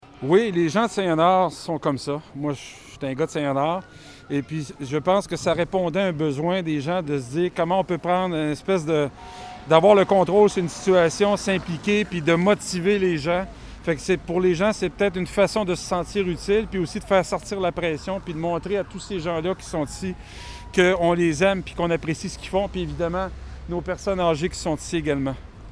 Faisant retentir sirènes et klaxons, des véhicules d’urgence et plusieurs dizaines d’automobiles ont défilé devant cette résidence pour personnes âgées de Saint-Léonard, dont la quasi-totalité des résidents et plusieurs employés ont contracté la COVID-19.